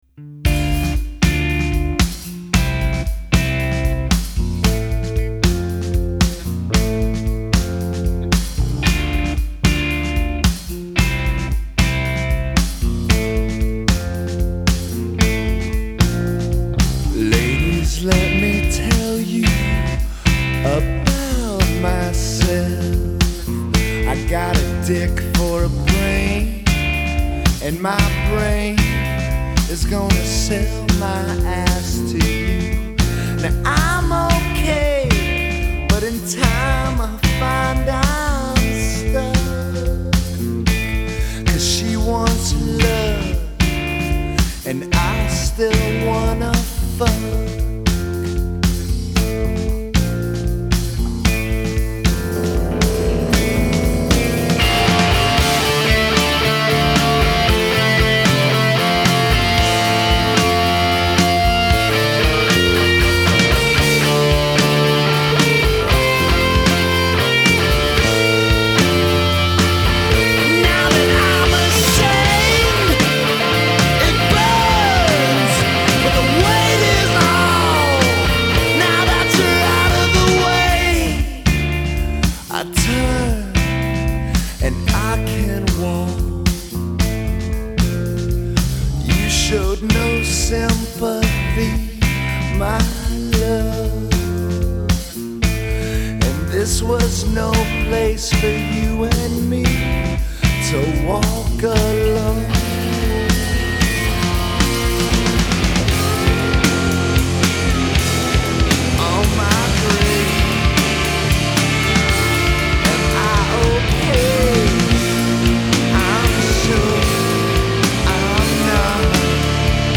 Жанр: alternative rock, post punk, grunge